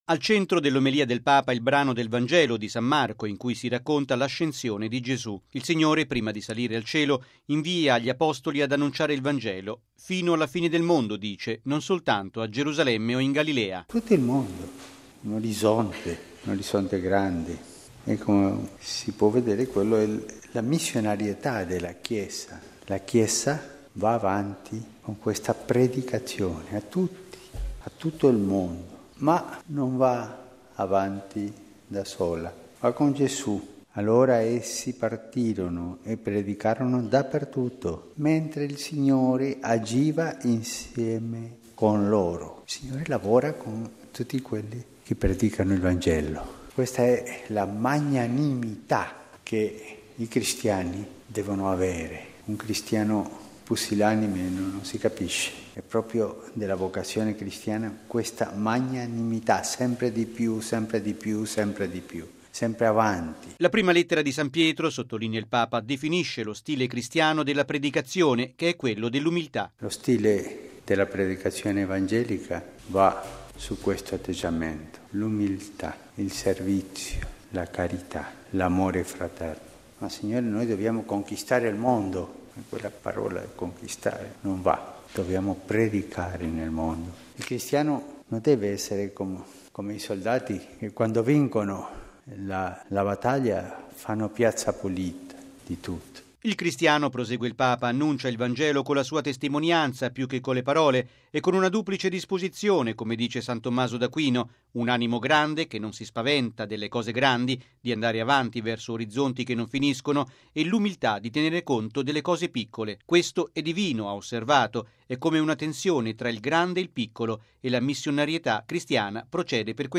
◊   Il Papa ha presieduto questa mattina, nella Domus Sanctae Marthae, la Messa nella Festa di San Marco Evangelista: lo stile dell’annuncio cristiano – ha detto nell’omelia – è umile ma nello stesso tempo non ha paura di operare cose grandi.